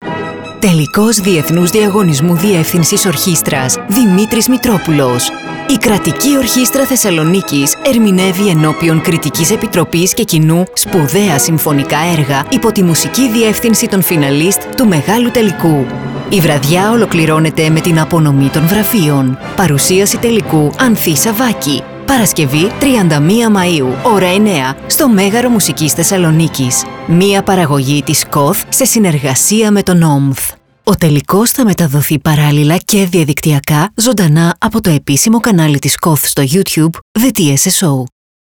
Κατηγορία: Ραδιοφωνικά σποτ
Ραδιοφωνικό σποτ 31μαι24_Τελικός Διαγωνισμού Δημήτρης Μητρόπουλος.mp3